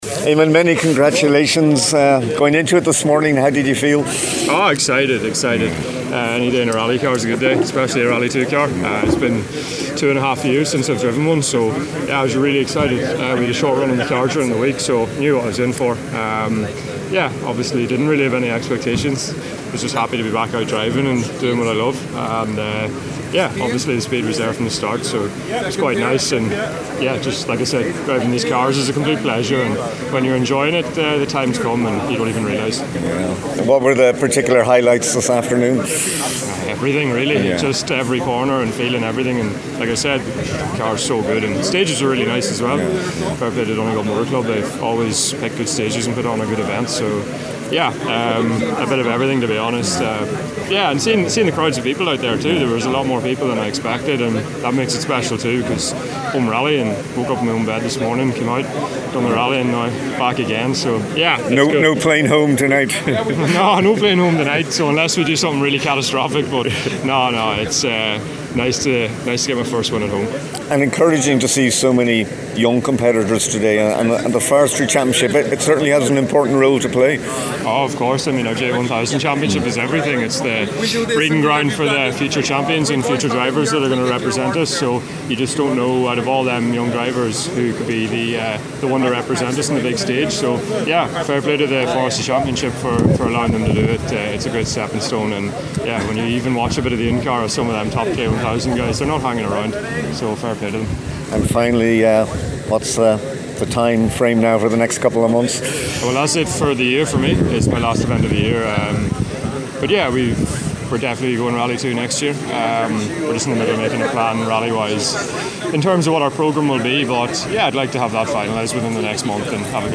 Donegal Forestry Rally – Reaction from the finish line